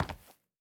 added stepping sounds
Linoleum_Mono_02.wav